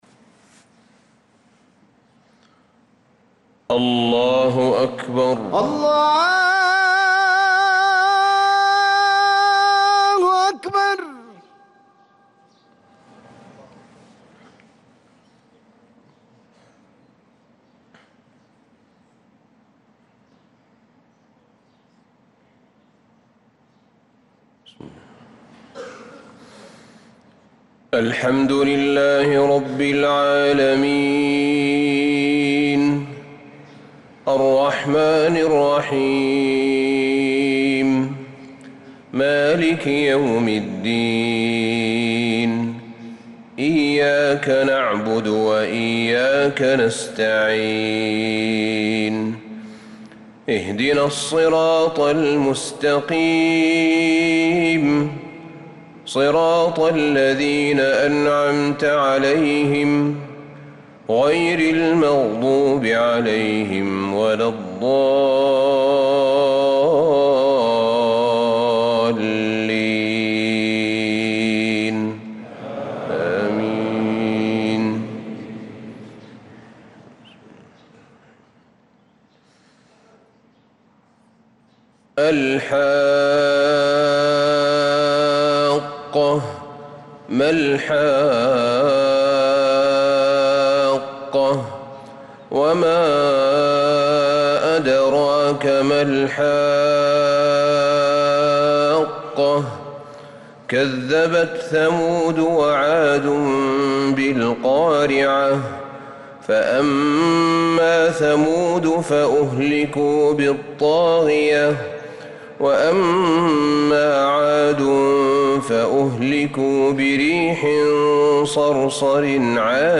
صلاة الفجر للقارئ أحمد بن طالب حميد 17 محرم 1446 هـ
تِلَاوَات الْحَرَمَيْن .